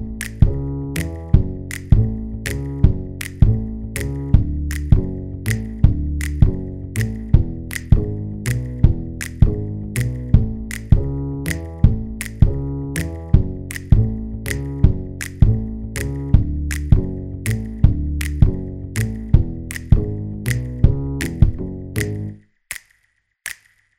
no Backing Vocals Rock 'n' Roll 3:51 Buy £1.50